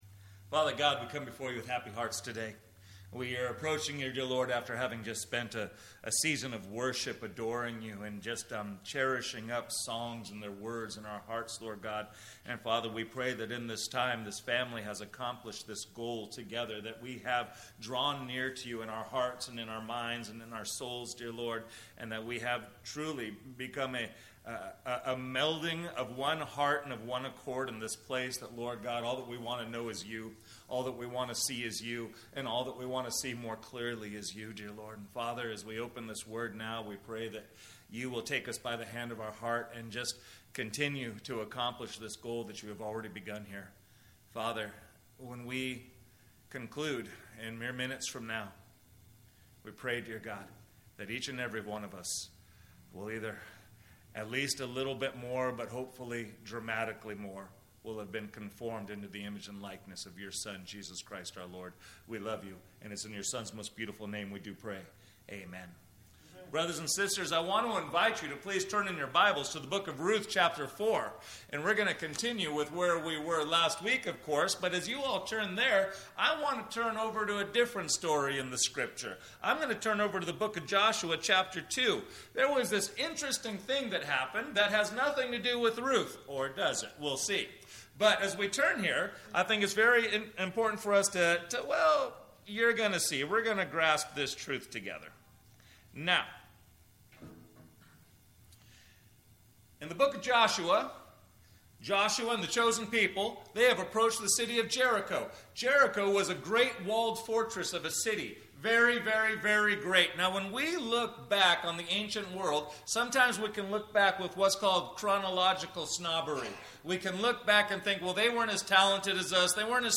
Note: First 20 seconds of recording are silent